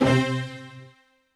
STR HIT C3 2.wav